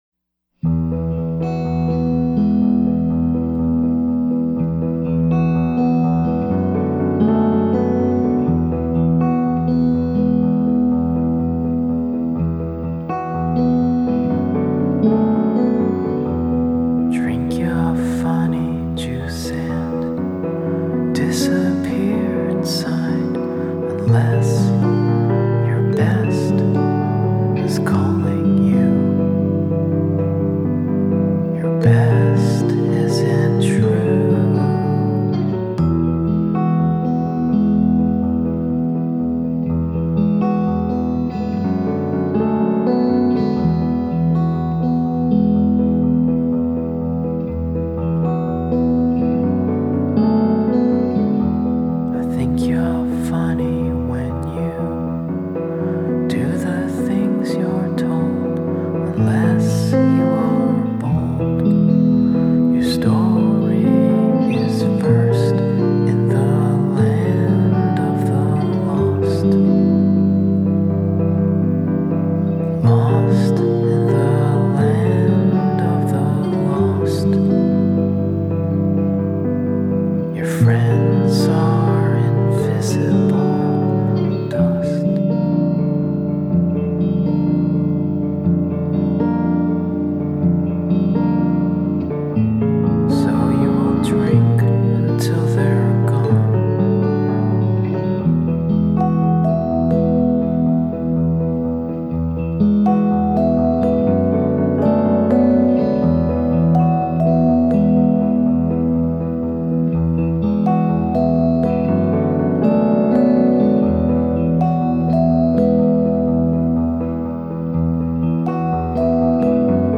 pop-folk